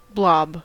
Ääntäminen
Vaihtoehtoiset kirjoitusmuodot BLOB Synonyymit lump blotch zerg Ääntäminen US : IPA : /blɑb/ UK : IPA : /blɒb/ Haettu sana löytyi näillä lähdekielillä: englanti Käännöksiä ei löytynyt valitulle kohdekielelle.